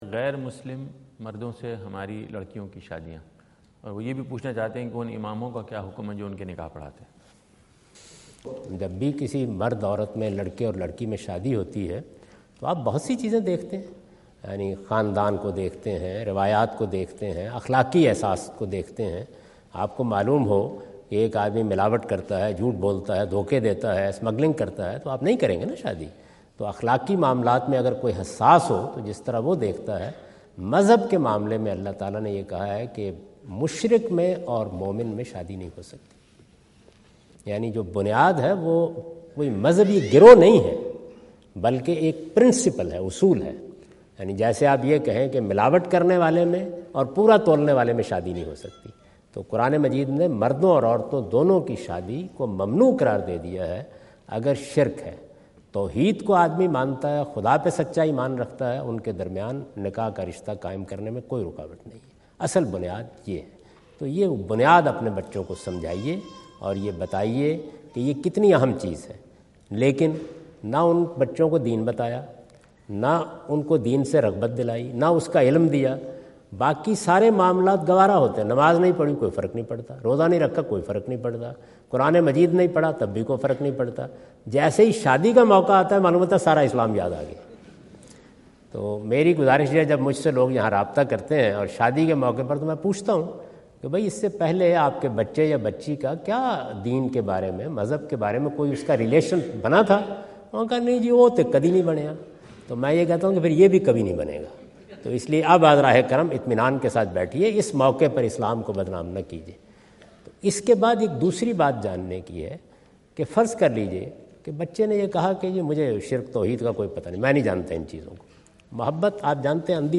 Javed Ahmad Ghamidi answer the question about "Marriages of Muslims Women with Non-Muslims" asked at North Brunswick High School, New Jersey on September 29,2017.